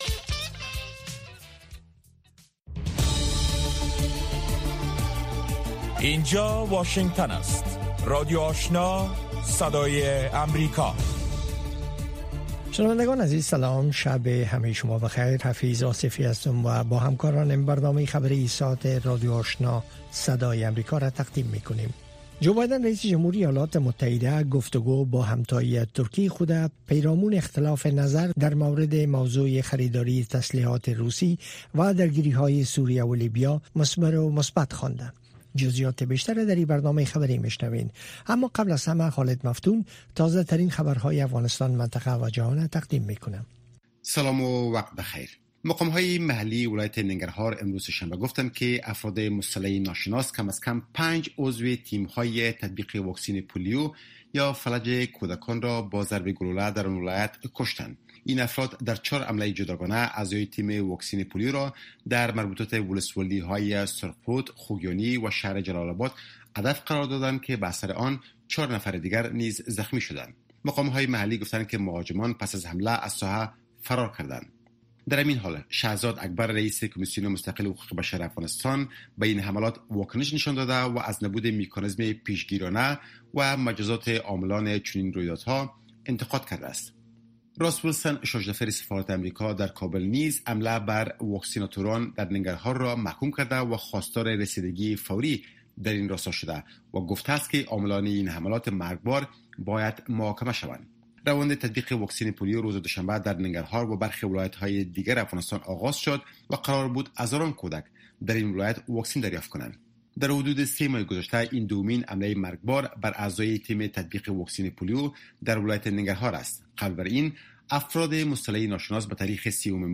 دومین برنامه خبری شب